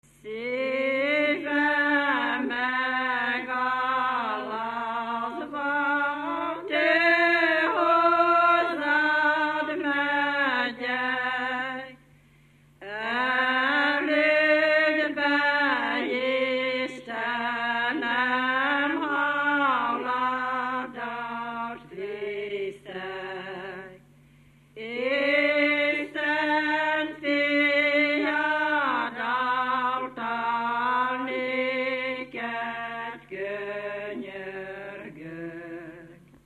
Dunántúl - Verőce vm. - Haraszti
ének
Stílus: 4. Sirató stílusú dallamok
Kadencia: 4 (1) b3 1